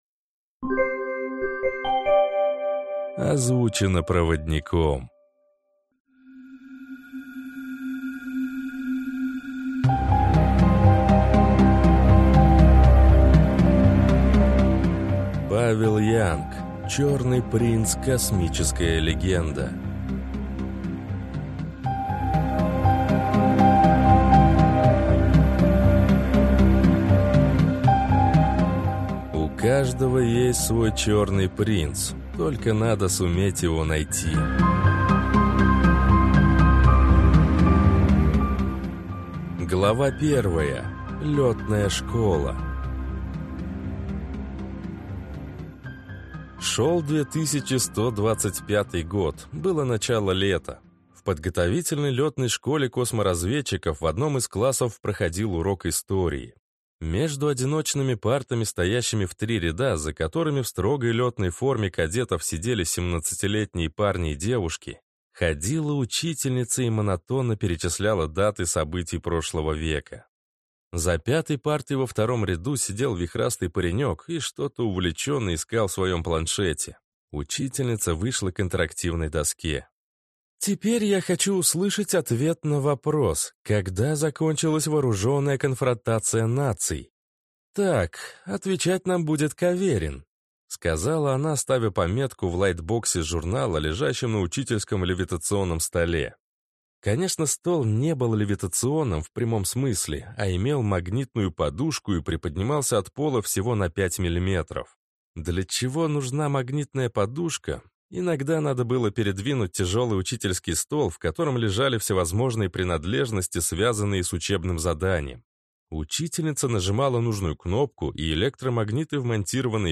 Аудиокнига Черный принц | Библиотека аудиокниг